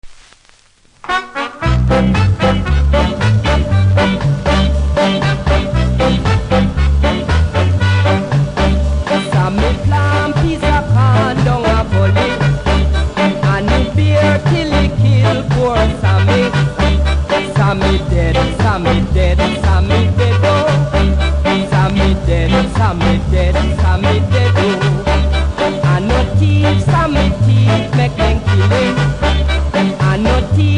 キズ多めですが音はそれほどでもないので試聴で確認下さい。